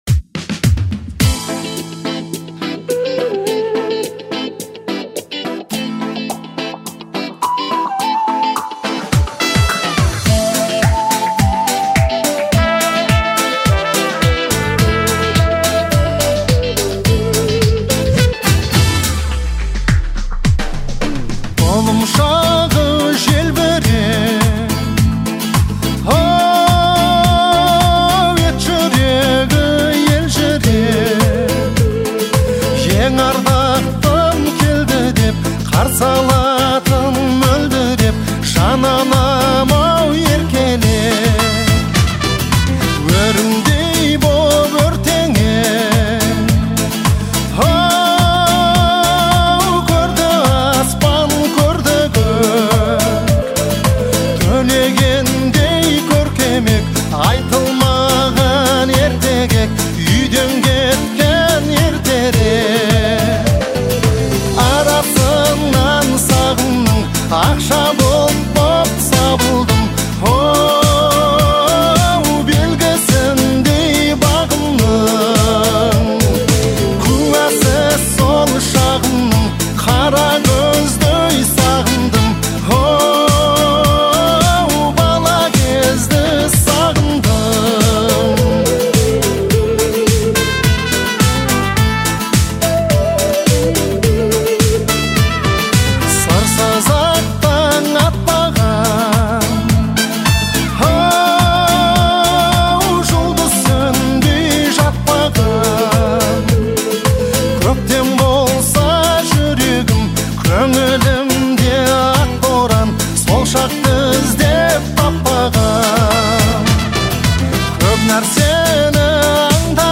кто ценит мелодичные и лиричные композиции